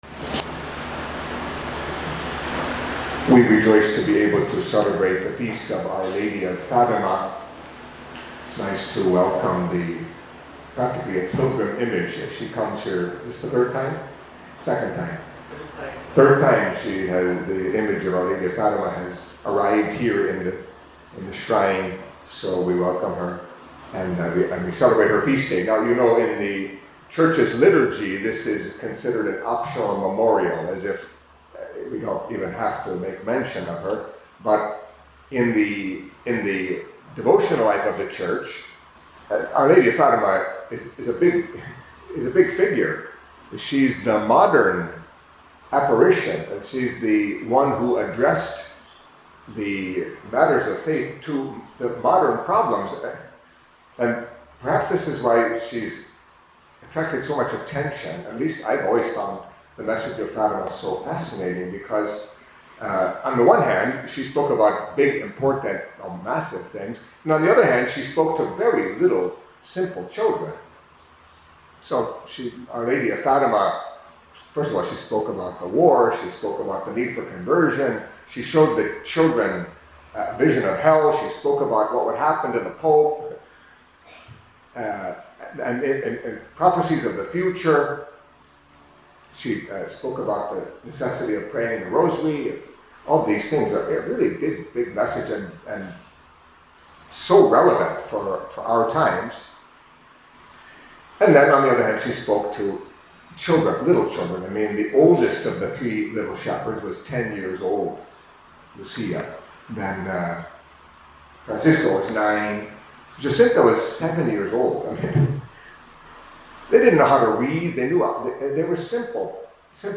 Catholic Mass homily for Tuesday of the Fourth Week of Easter